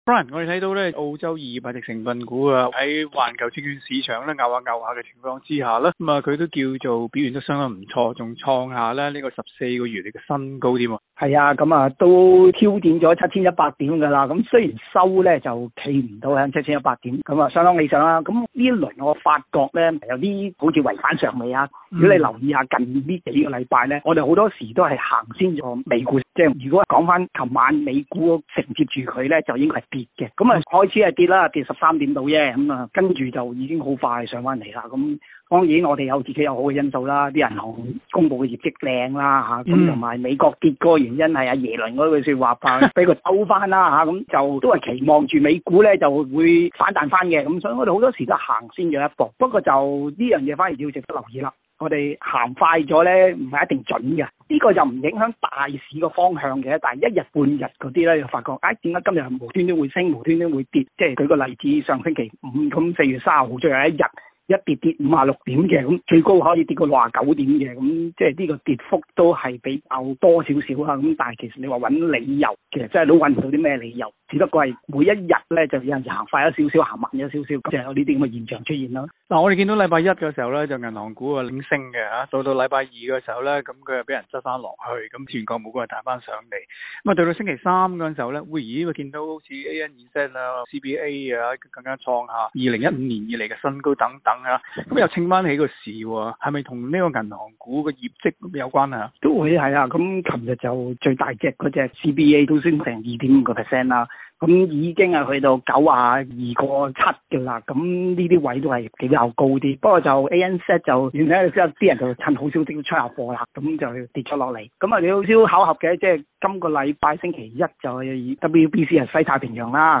详情请收听今日的访问环节。